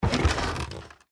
diving_treasure_pick_up.ogg